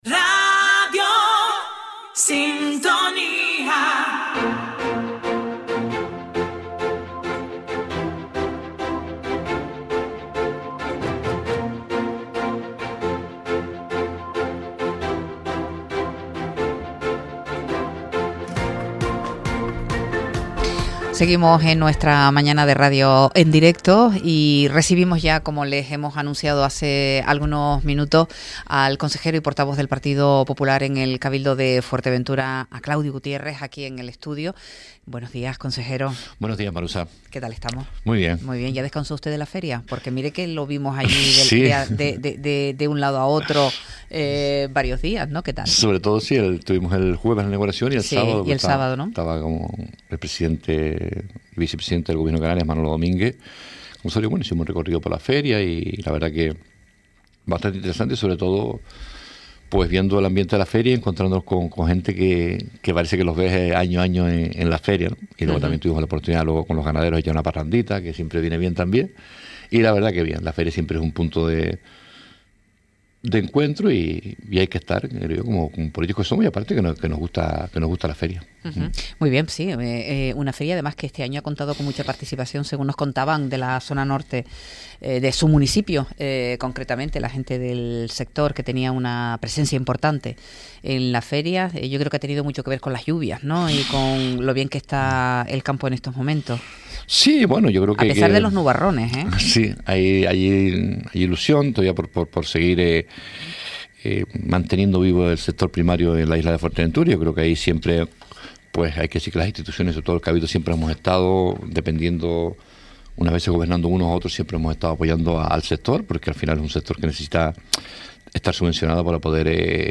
Entrevista a Claudio Gutiérrez, consejero por el PP en el Cabildo de Fuerteventura - Radio Sintonía